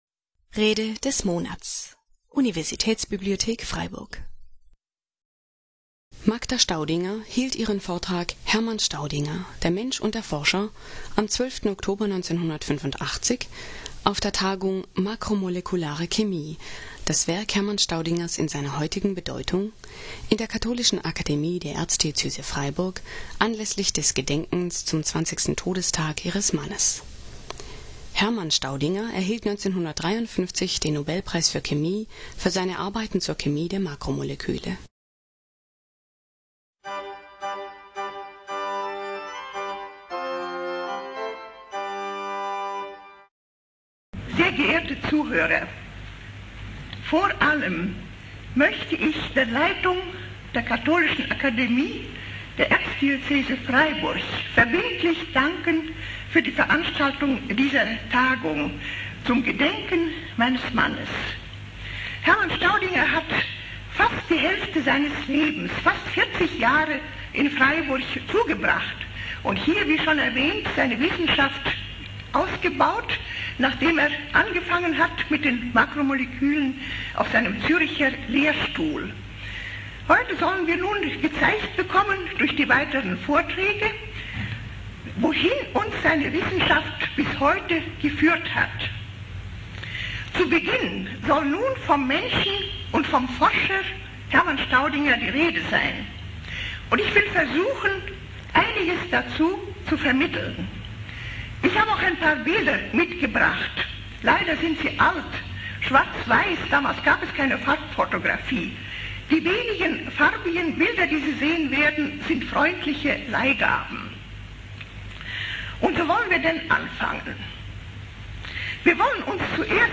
Rede des Monats